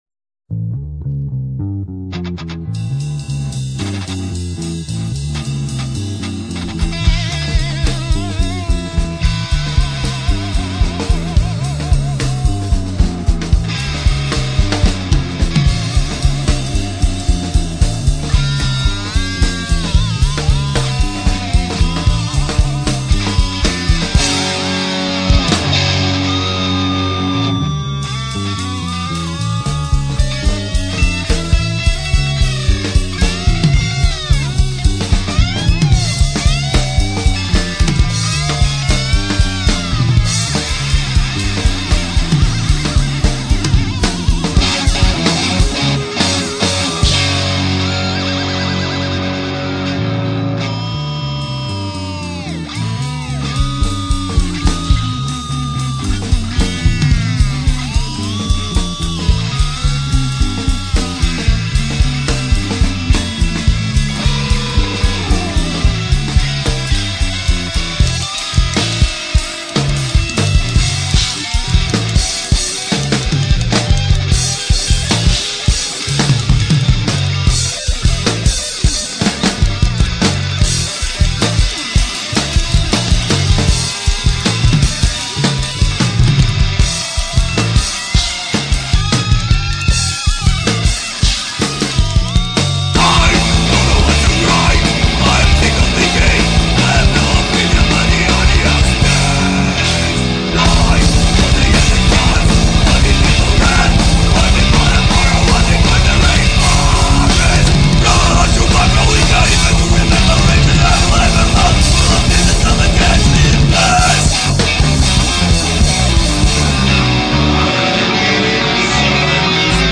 For Metal,
Guitars / Bass / Synths / Spoons
Drums
Voice
Choir